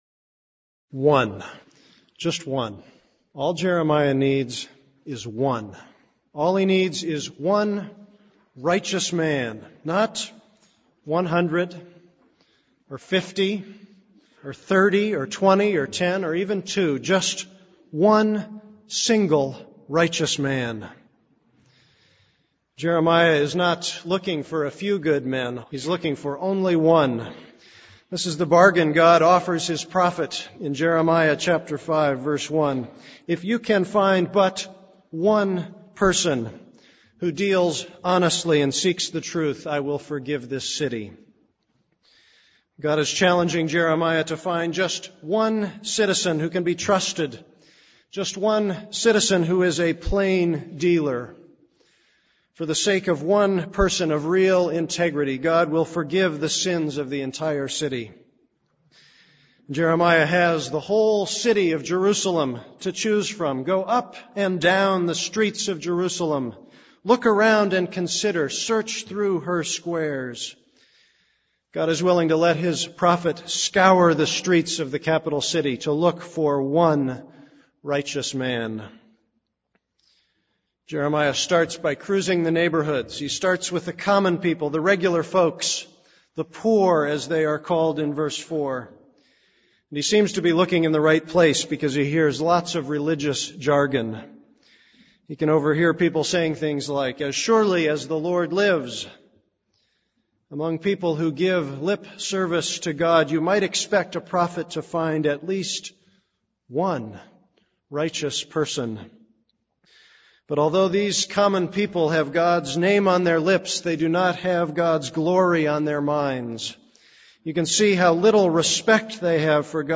This is a sermon on Jeremiah 5:1-19.